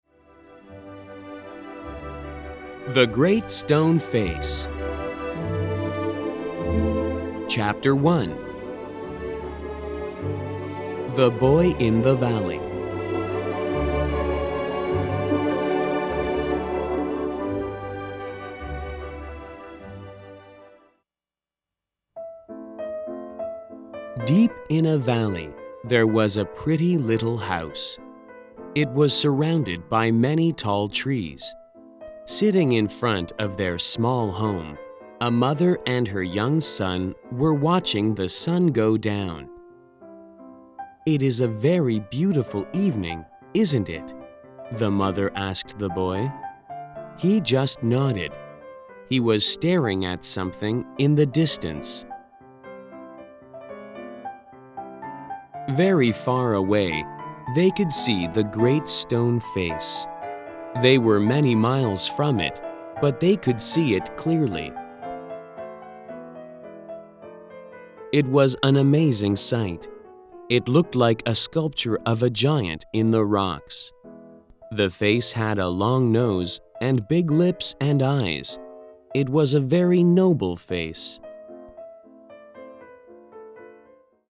本書生動活潑的朗讀音檔，是由專業的美國播音員所錄製；故事是由以英文為母語的專業編輯，參照教育部公布的英文字彙改寫而成，對於所有學生將大有助益。
In the audio recording of the book, texts are vividly read by professional American actors.